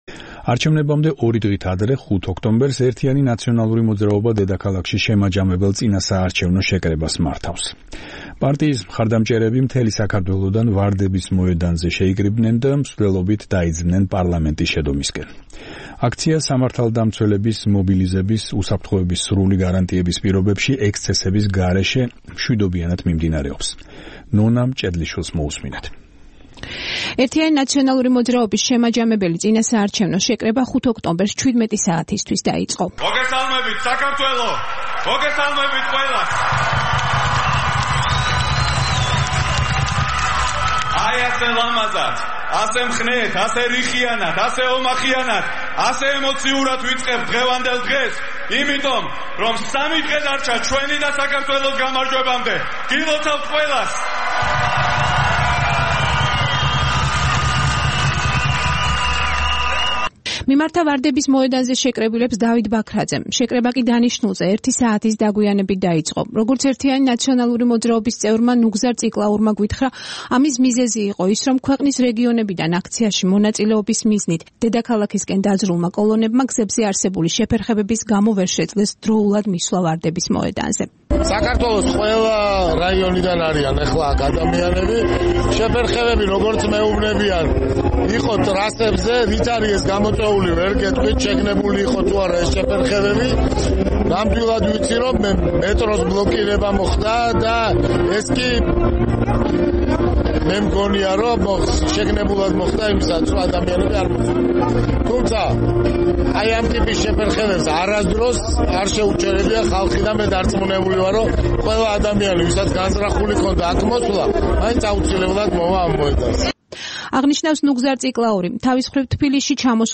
მიხეილ სააკაშვილი მიმართავს აქციის მონაწილეებს